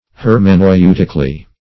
Search Result for " hermeneutically" : The Collaborative International Dictionary of English v.0.48: Hermeneutically \Her`me*neu"tic*al*ly\, adv. According to the principles of interpretation; as, a verse of Scripture was examined hermeneutically.
hermeneutically.mp3